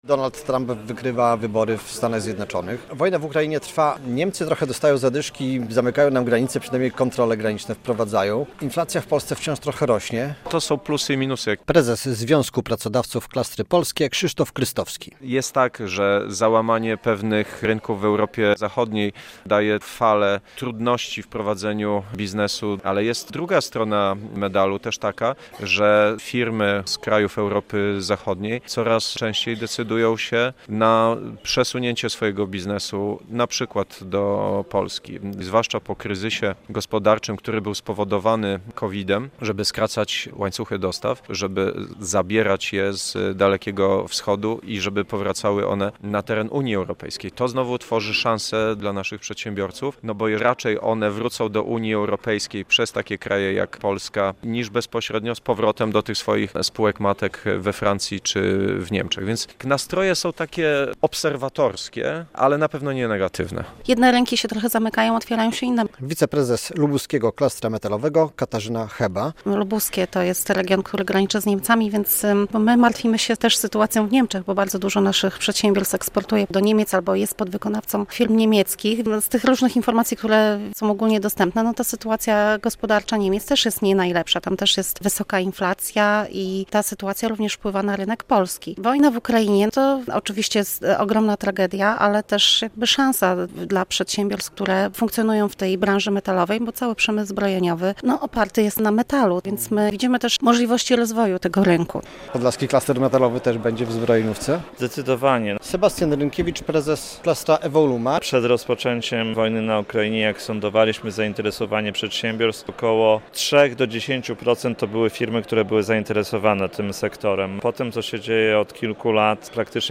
O sektorze zbrojeniowym i czekaniu na KPO na Kongresie Klastrów Polskich